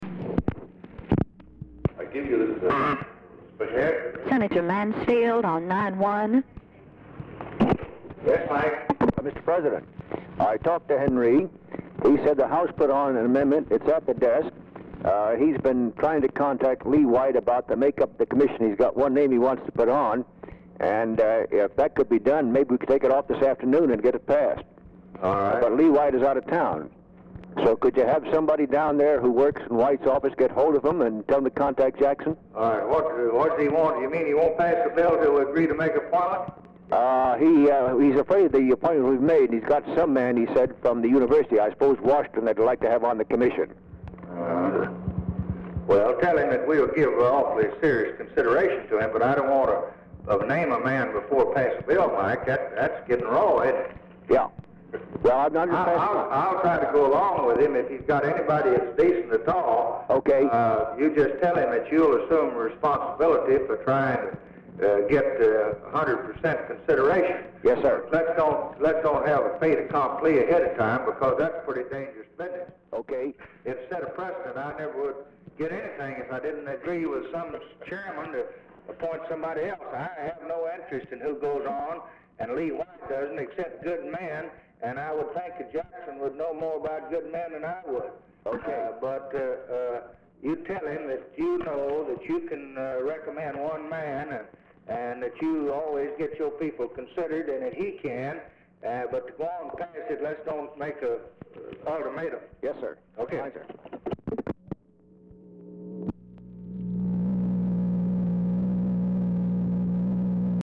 POOR SOUND QUALITY IN MIDDLE OF CONVERSATION
Format Dictation belt
Location Of Speaker 1 Oval Office or unknown location
Specific Item Type Telephone conversation